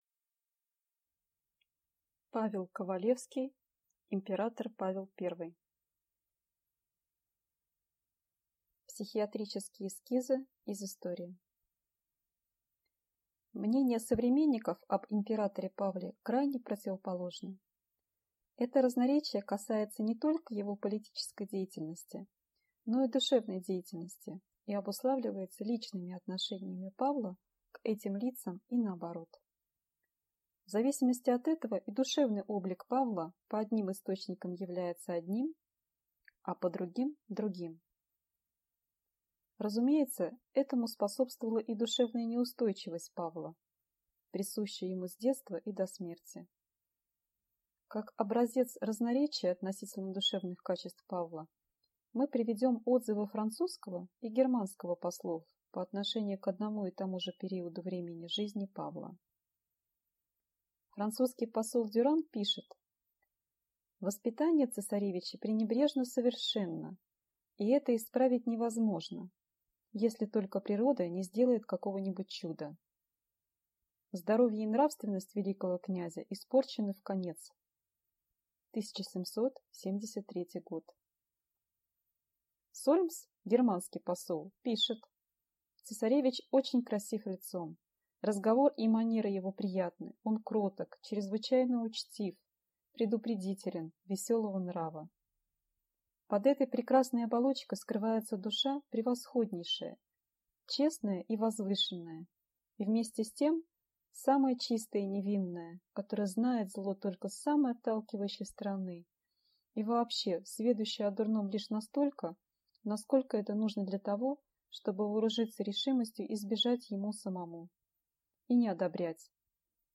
Аудиокнига Император Павел I | Библиотека аудиокниг